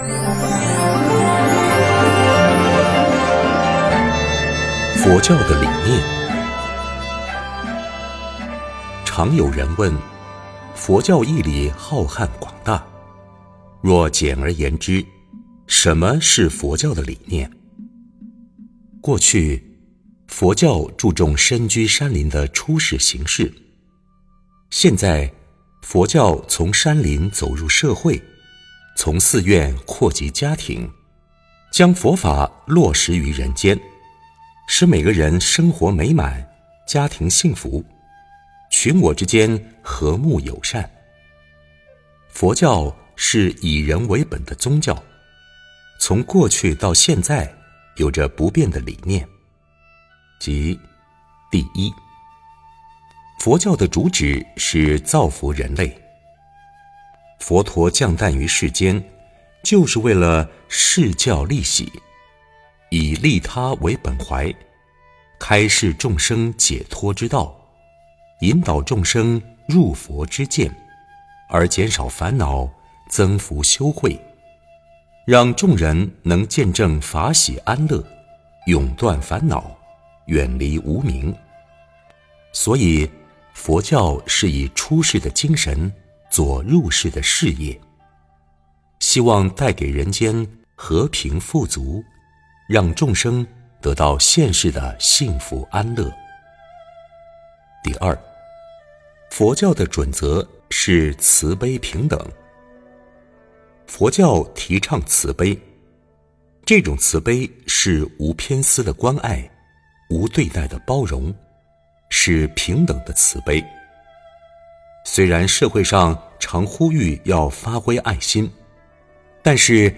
佛音 冥想 佛教音乐 返回列表 上一篇： 清净法身佛--天籁梵音 下一篇： 06.